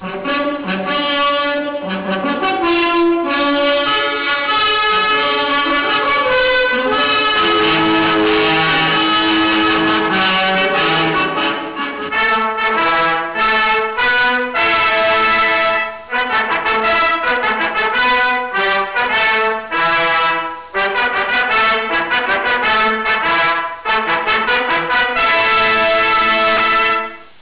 Fanfare (0.30)